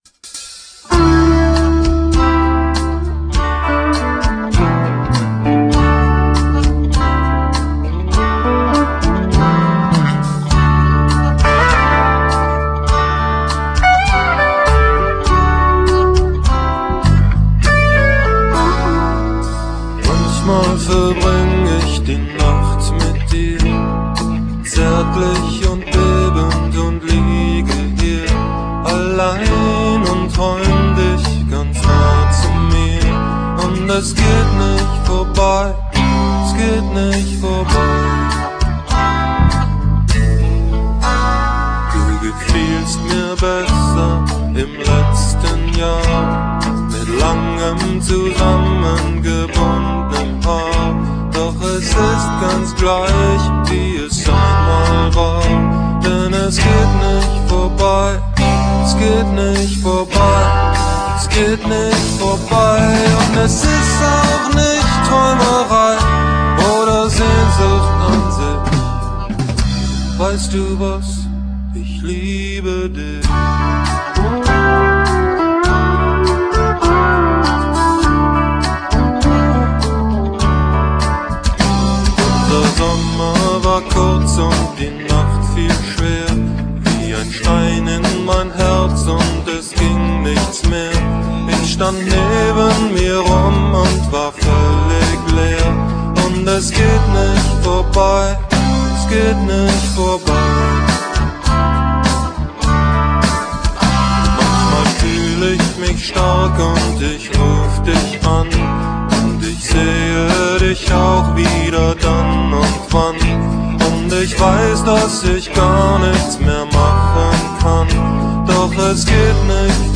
Gesang, Gitarre
Bass
Sologitarre
Schlagzeug
**** Demo 4/88   ***** Technologiezentrum DU 6.3.94